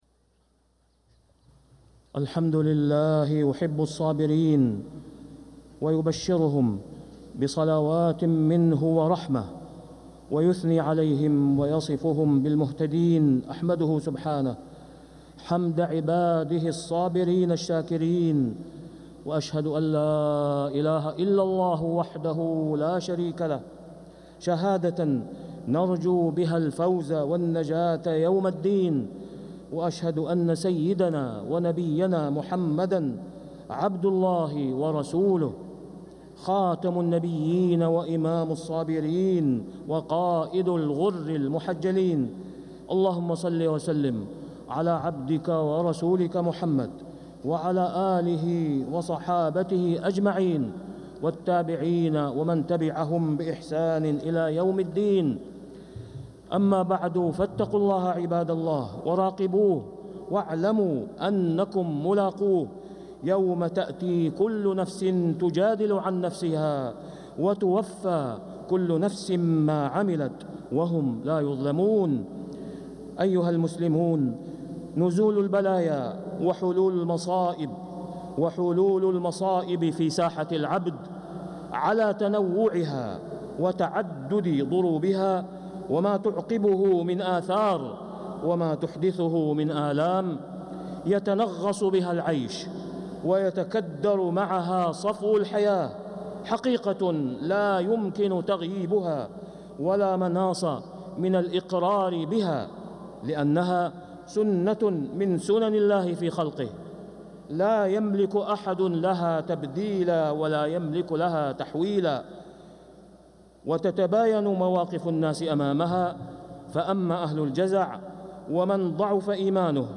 خطبة الجمعة 4 ذو القعدة 1446هـ | Khutbah Jumu’ah 2-5-2025 > خطب الحرم المكي عام 1446 🕋 > خطب الحرم المكي 🕋 > المزيد - تلاوات الحرمين